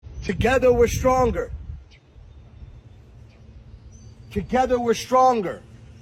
dj khaled saying together were stronger